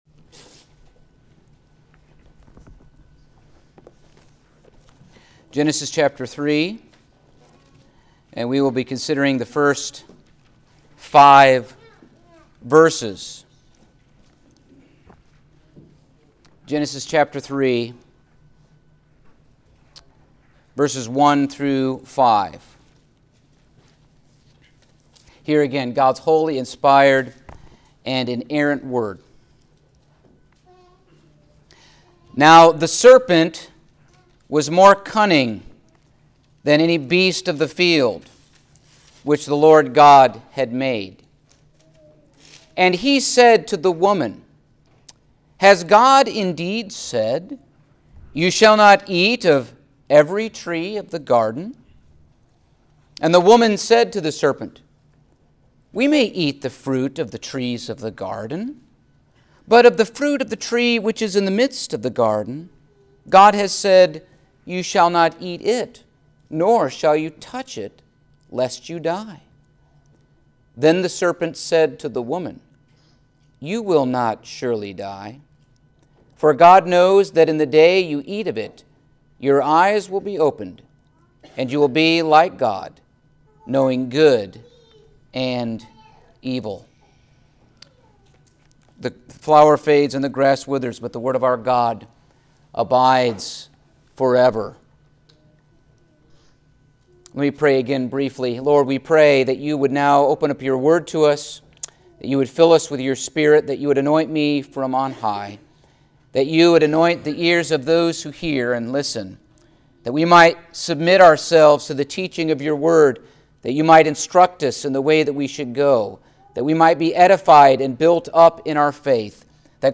Passage: Genesis 3:1-5 Service Type: Sunday Morning